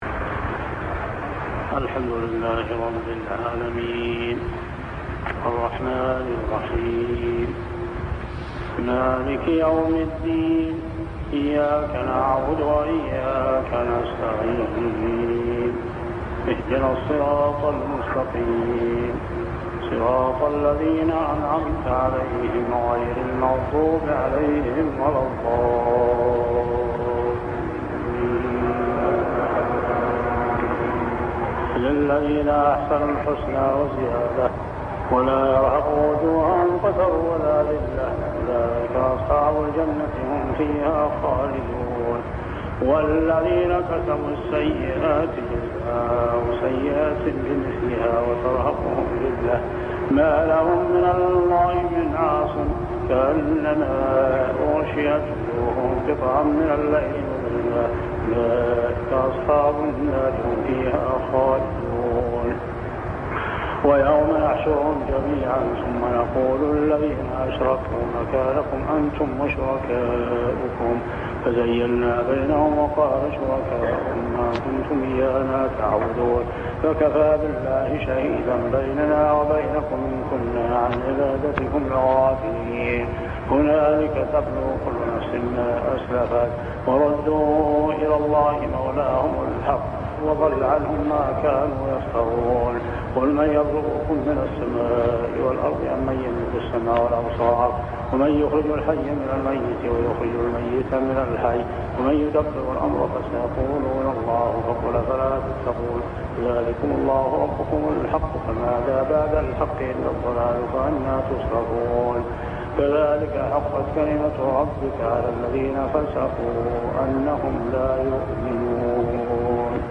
صلاة التراويح عام 1401هـ سورة يونس 26-50 | Tarawih prayer Surah Yunus > تراويح الحرم المكي عام 1401 🕋 > التراويح - تلاوات الحرمين